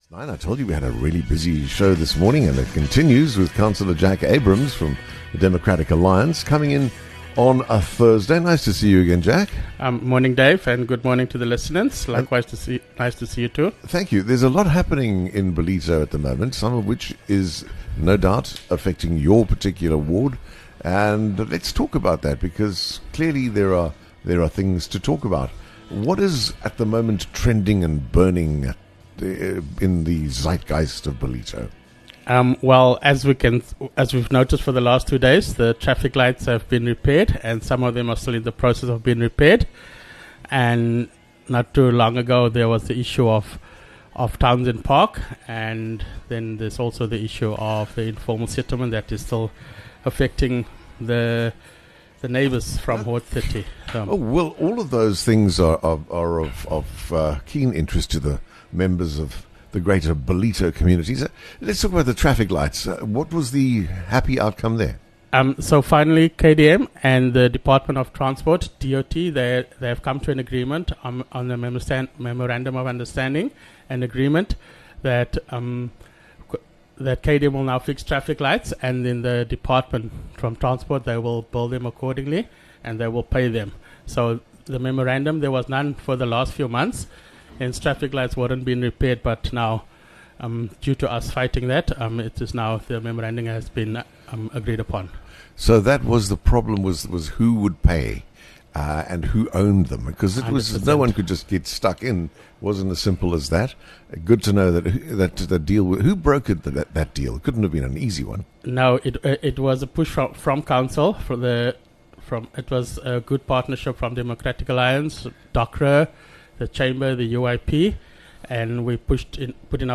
11 Dec Counselor Jack Abraham speaks about what is happening in Ballito